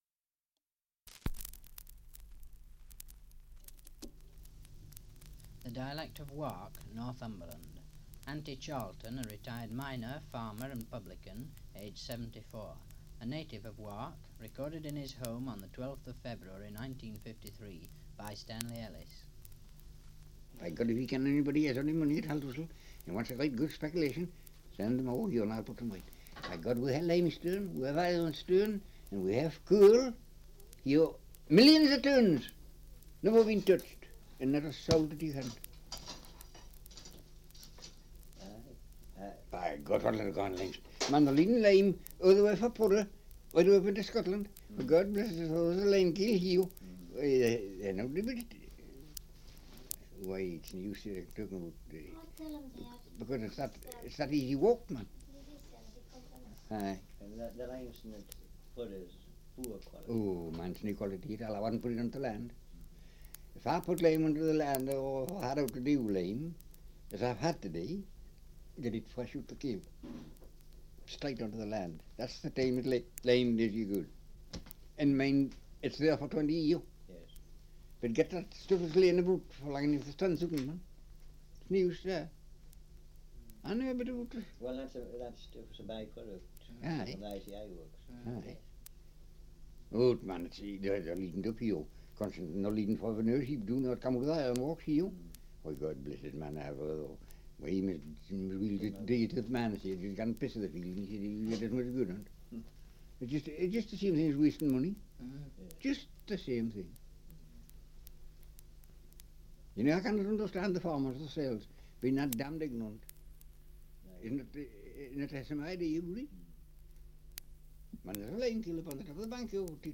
Survey of English Dialects recording in Wark, Northumberland
78 r.p.m., cellulose nitrate on aluminium